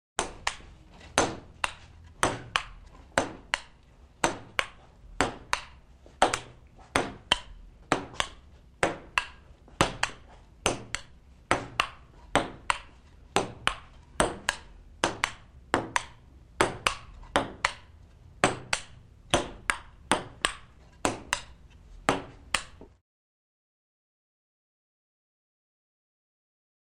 Звуки настольного тенниса
Звук пинг-понга - есть такой вариант